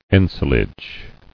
[en·si·lage]